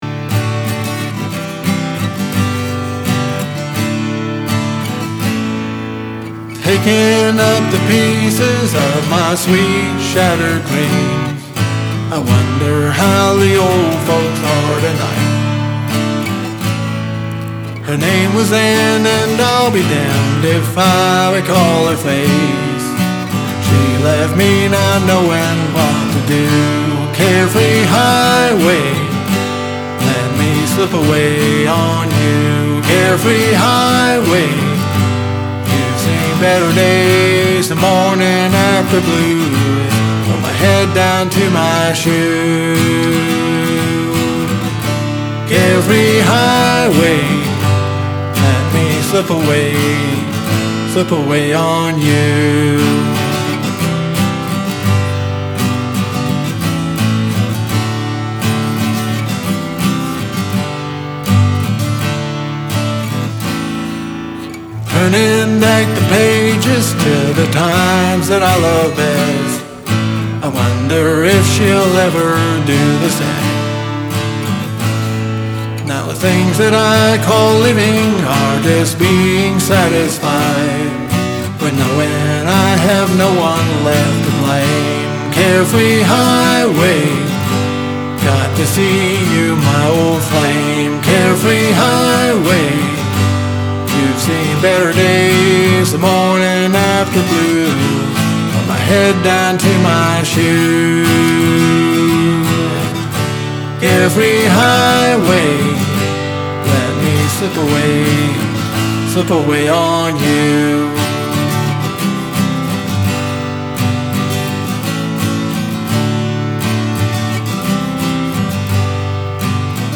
It still isn't perfect, but a lot better than it was originally. After all work fixing the vocal manually, I decided to replace original vocal with the fixed vocal stem in my original mix. and then go from there. I'm running it through the same fx chain minus the melodine.
I also added reverb to the guitar.